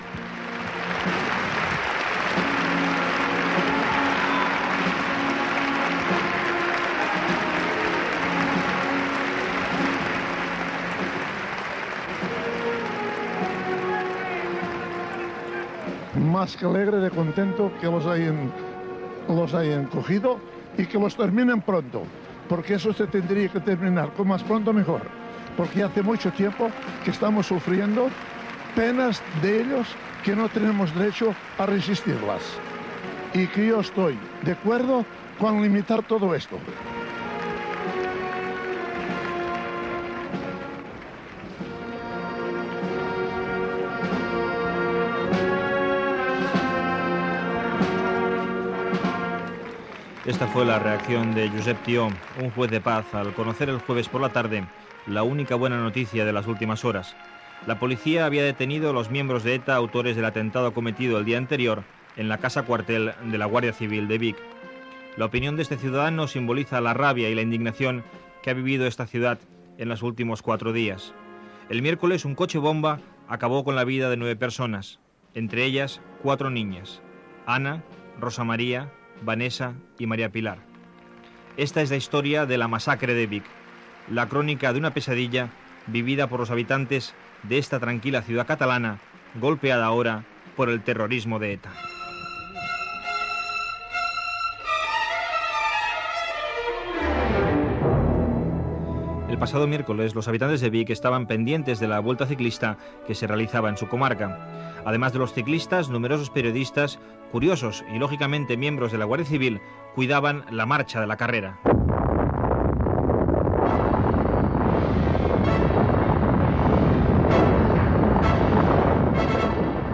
Reportatge sobre l'atemptat d'ETA a la caserna de la Guardia Civil de Vic perpetrat el 29 de maig de 1991.
Informatiu